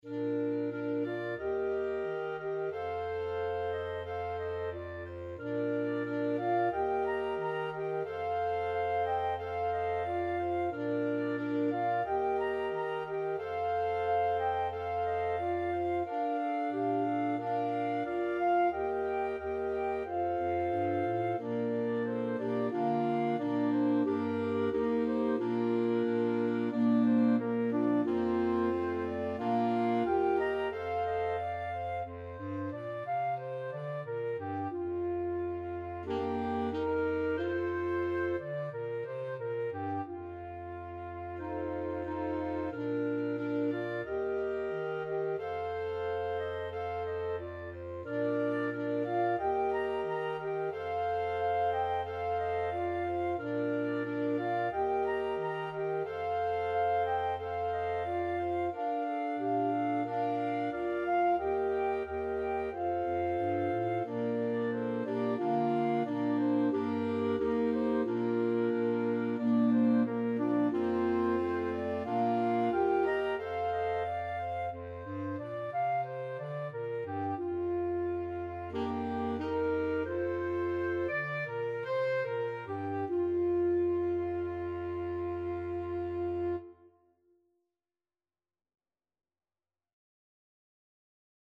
Free Sheet music for Flexible Mixed Ensemble - 5 Players
FluteViolin
ClarinetViolin
Clarinet
Alto SaxophoneFrench Horn
Bass ClarinetCello
A popular Chinese folk song, dating back to the 18th century.
Andante =c.90
4/4 (View more 4/4 Music)
Bb major (Sounding Pitch) (View more Bb major Music for Flexible Mixed Ensemble - 5 Players )